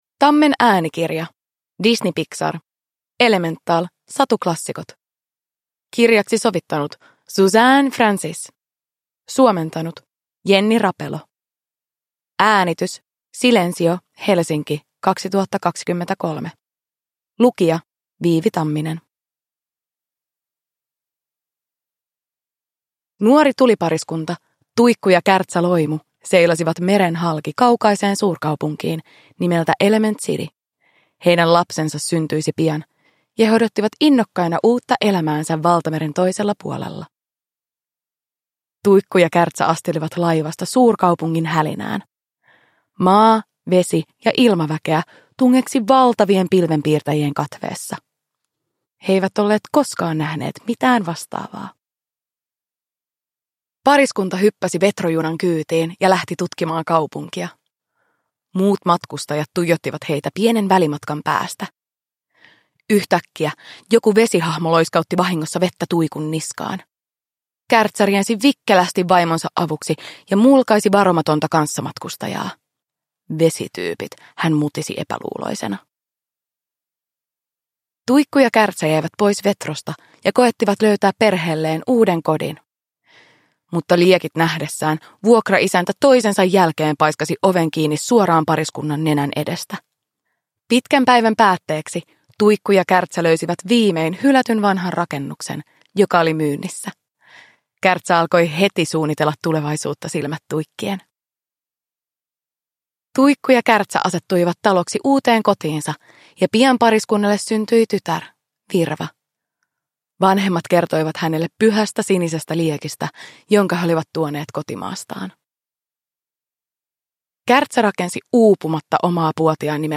Disney Pixar. Elemental. Satuklassikot – Ljudbok – Laddas ner